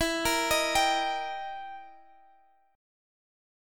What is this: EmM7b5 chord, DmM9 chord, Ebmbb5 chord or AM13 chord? EmM7b5 chord